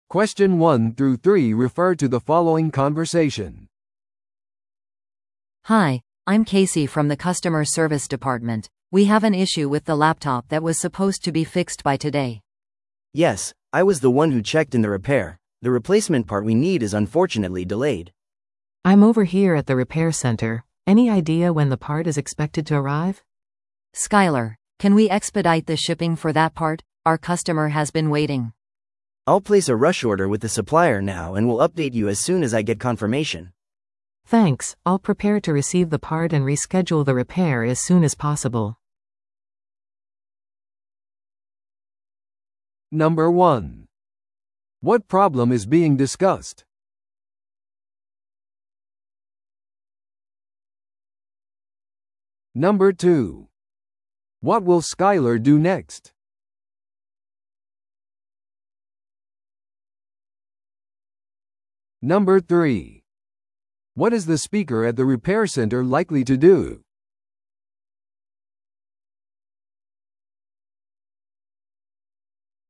TOEICⓇ対策 Part 3｜パソコン修理の部品遅延について – 音声付き No.140